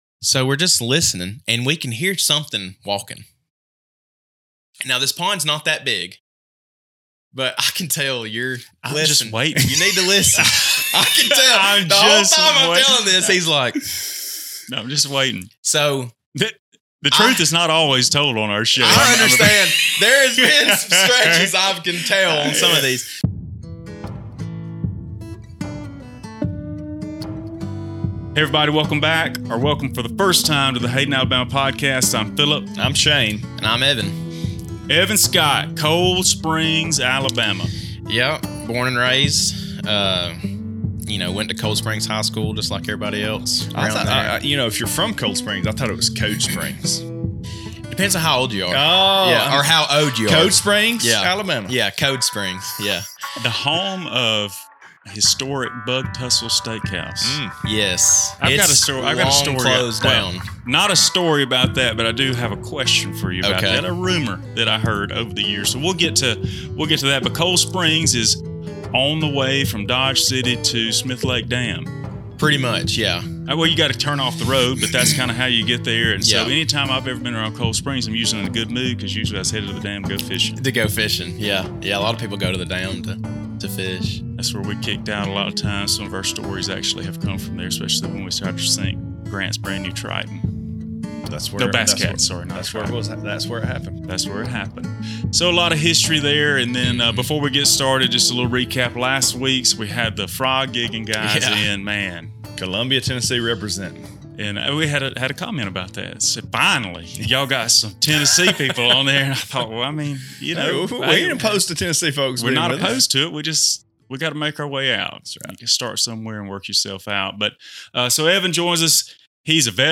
A Cold Springs, Alabama resident and Iraqi Veteran joins us on the show this week. One theme runs throughout the episode--unexpected sightings and events. He tells stories about panther sightings, time in Iraq, and a hilarious encounter in Austin, Texas that happened one night in his early military career.